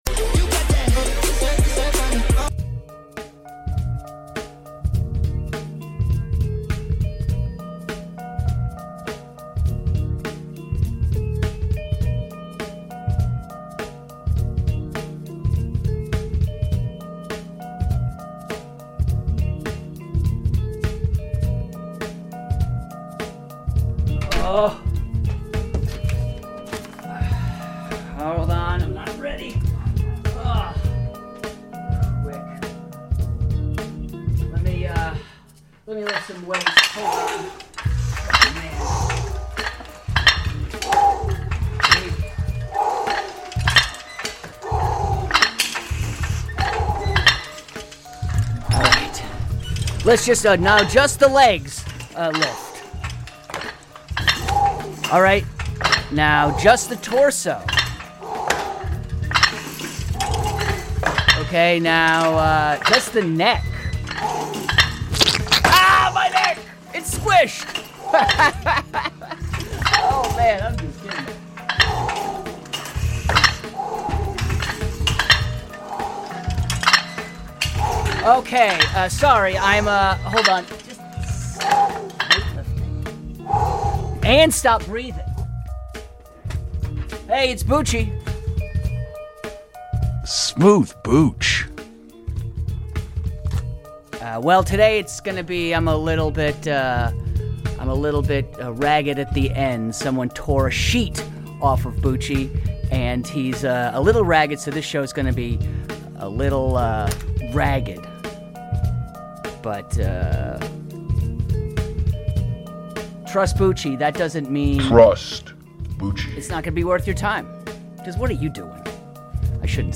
Talk Radio
Radio Comedy